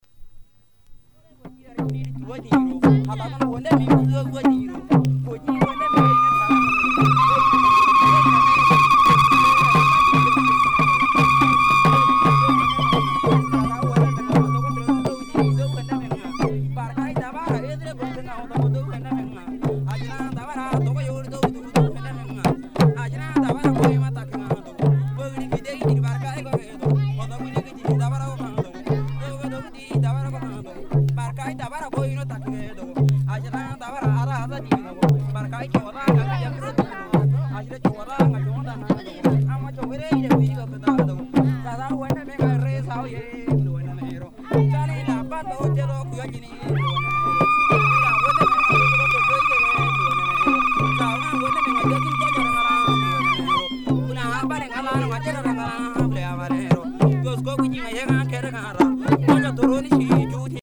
The killi is an idiophone, a brass bell measuring approximately 10 cm in height, with an internal clapper angular in shape and ending in a sharp point.
It is attached to clothing or worn as a necklace and therefore rings as the body moves.